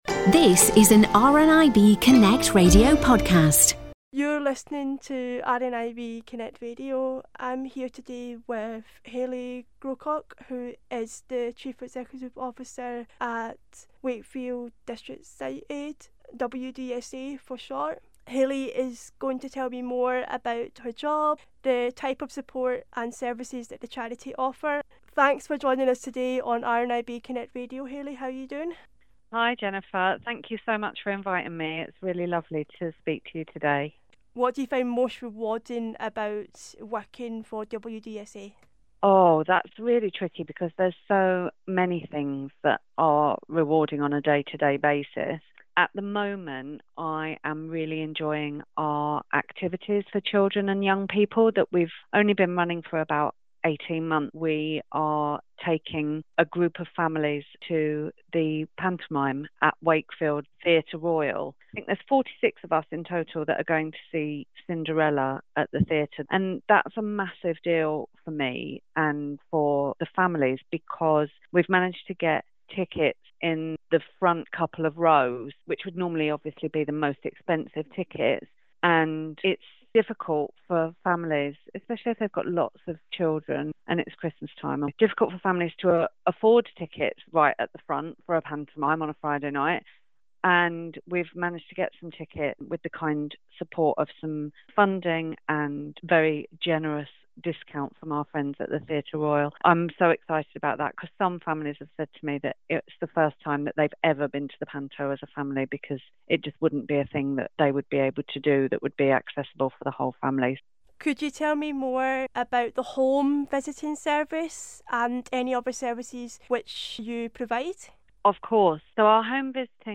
Wakefield District Sight Aid (WDSA) , Interview